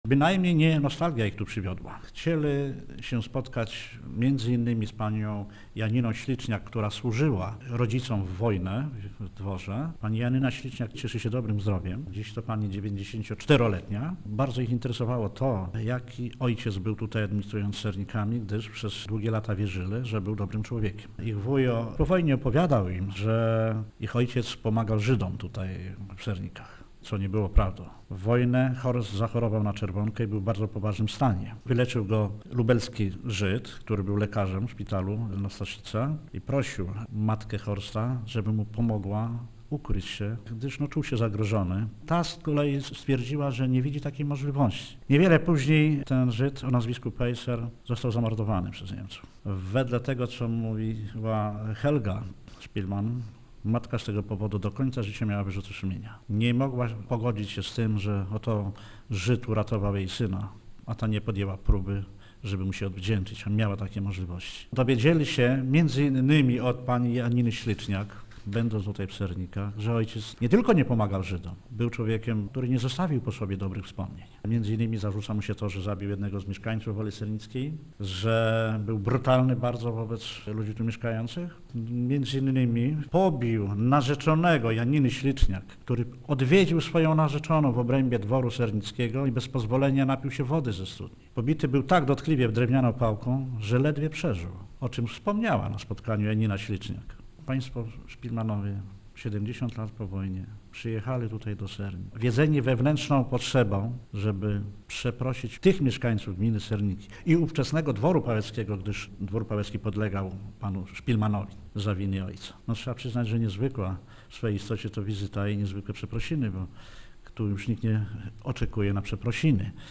wspomina wójt Stanisław Marzęda: